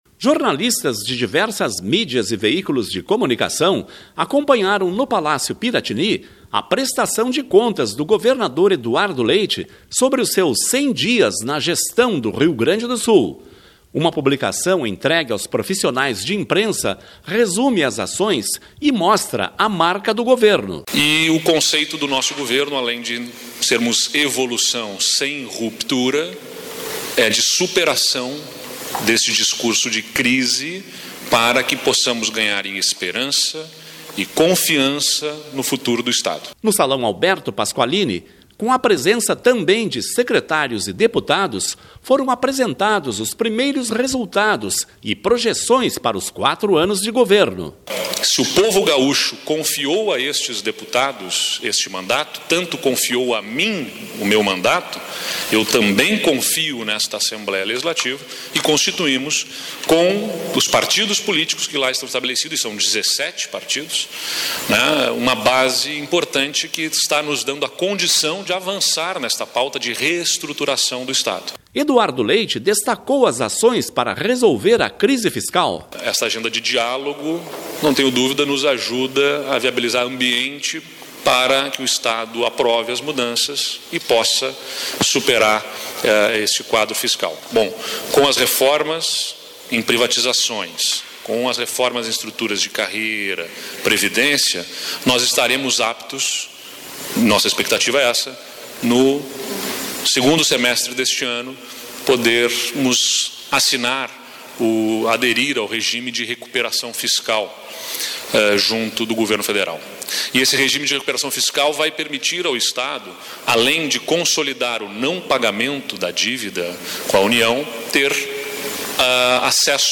Jornalistas de diversas mídias e veículos de comunicação acompanharam no Palácio Piratini a prestação de contas do governador Eduardo Leite sobre os seus 100 dias na gestão do Rio Grande do Sul. Foi apresentada a marca do Governo.